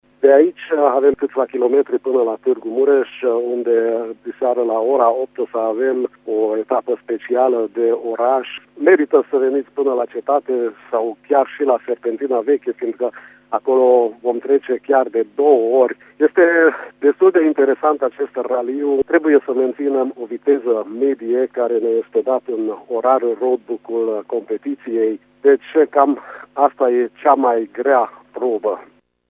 Unul din participanții la Raliu